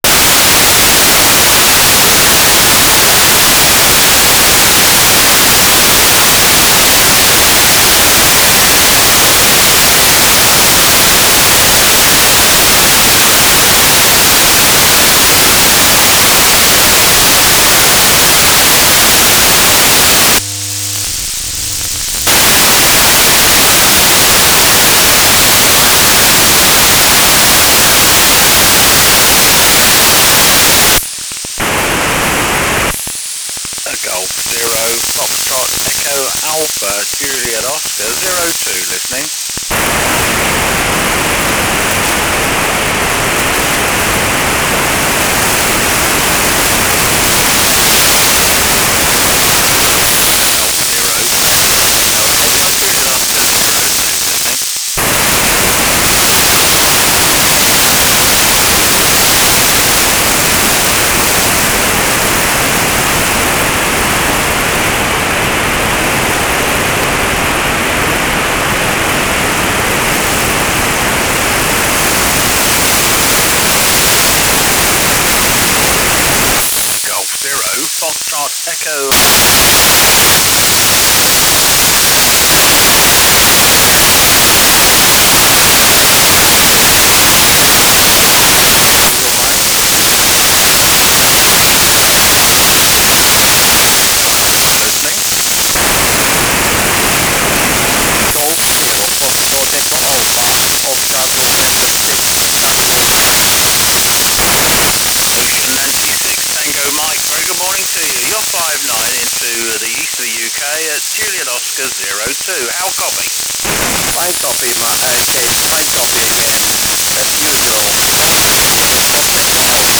"transmitter_mode": "USB",